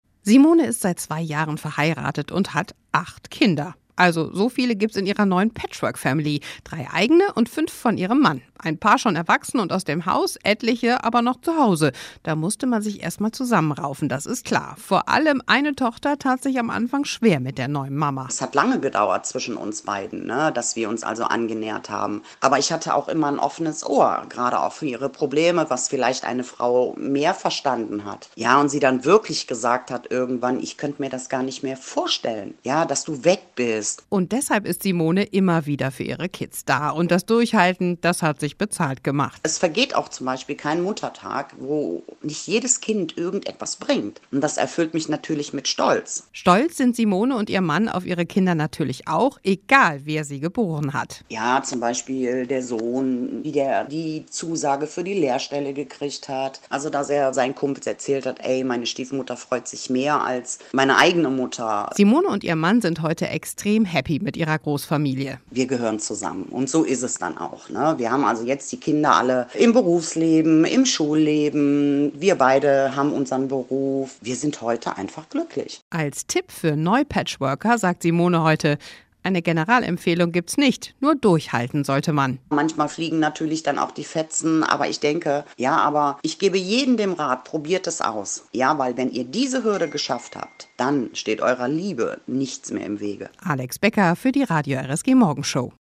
Wie fühlt sich das an, plötzlich die neue Mama mehrerer "Bonus-Kinder" zu sein? Mehrere RSG-Hörer haben uns in der Morgenshow von ihrem Familiengeschichten erzählt.